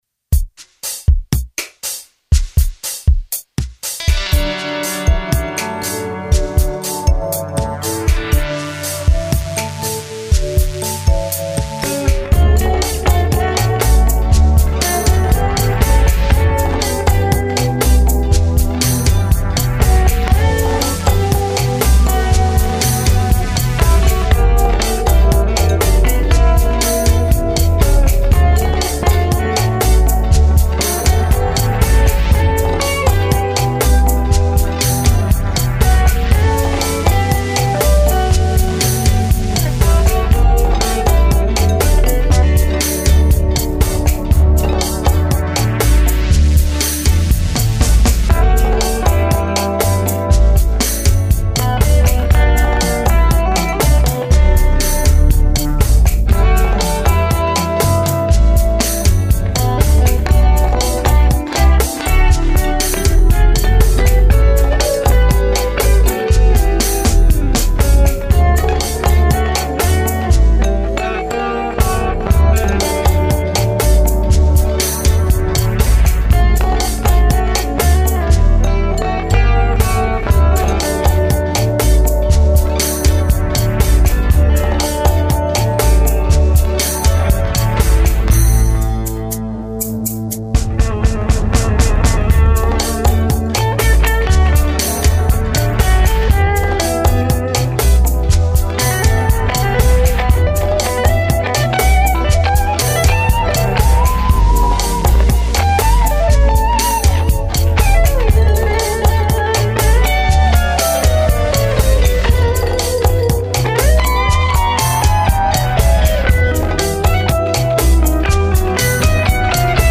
has a gentle flow to it